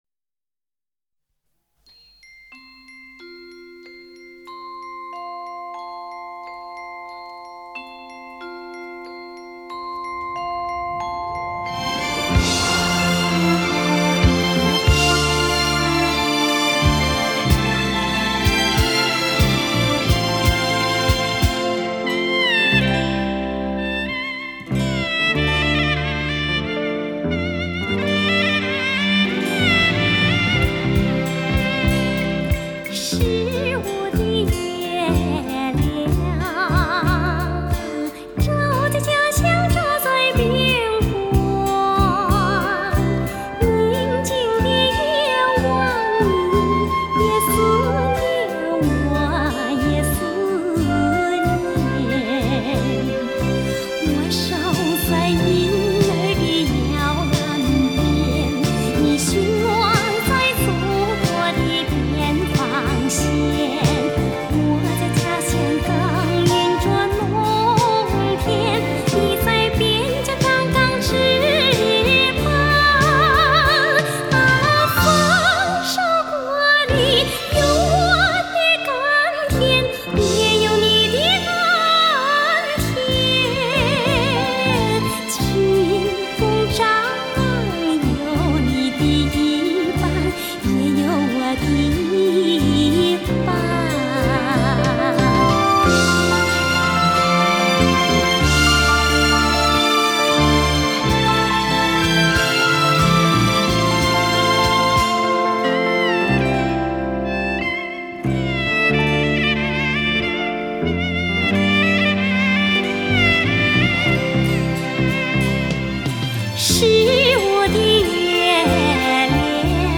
音乐风格: 流行